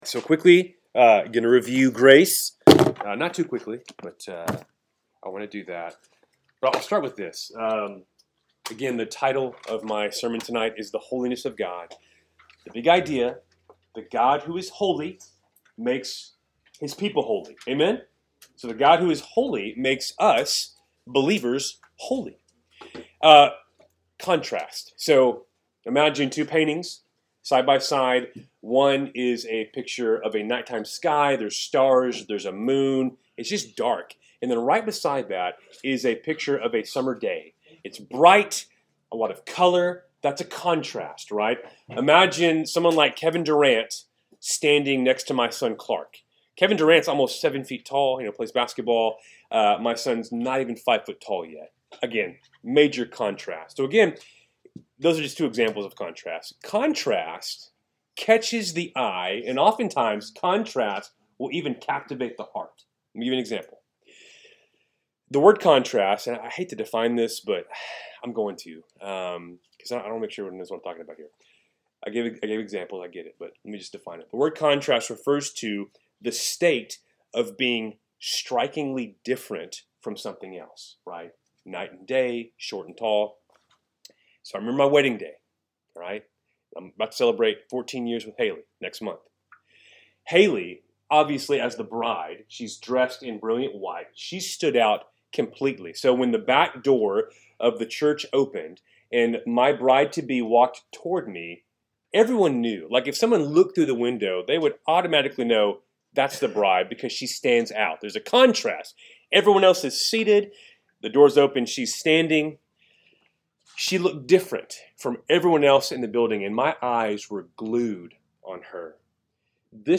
3-5-25-Wednesday-Night-Bible-Study.mp3